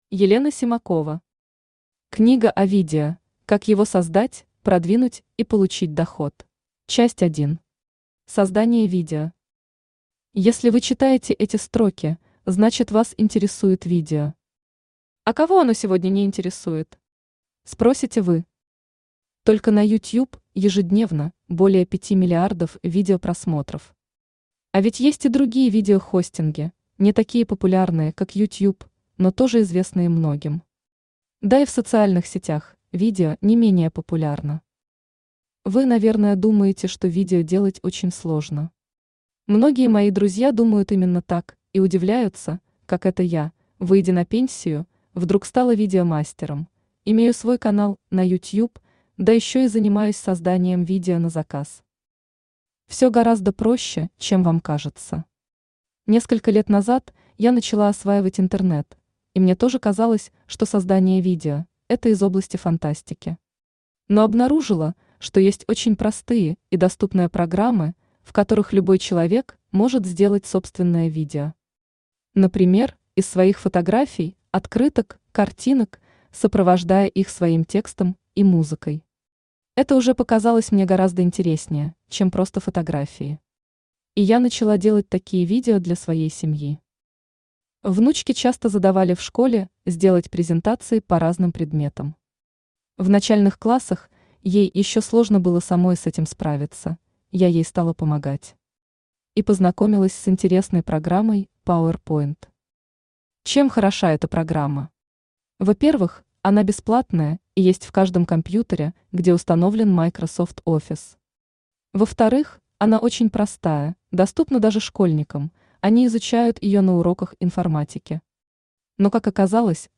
Аудиокнига Книга о видео: как его создать, продвинуть и получить доход | Библиотека аудиокниг
Aудиокнига Книга о видео: как его создать, продвинуть и получить доход Автор Елена Симакова Читает аудиокнигу Авточтец ЛитРес.